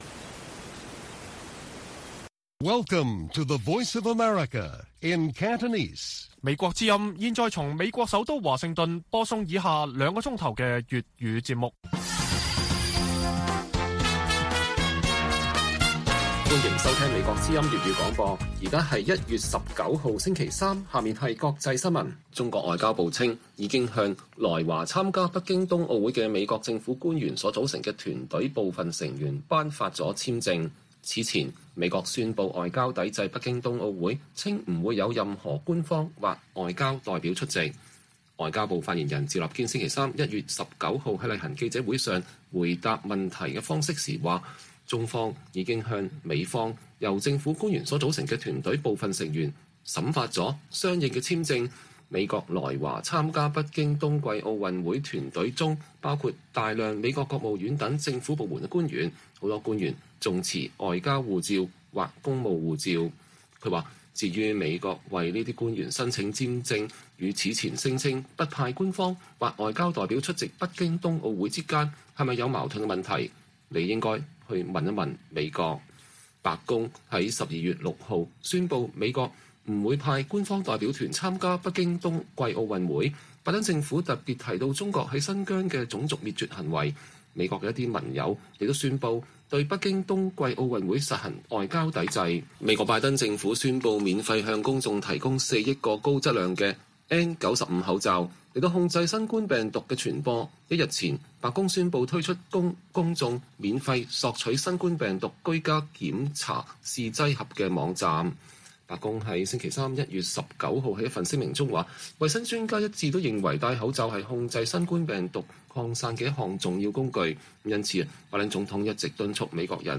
粵語新聞 晚上9-10點: 香港前本土派社運領袖梁天琦凌晨出獄 刪社交專頁禁受訪安排前所未有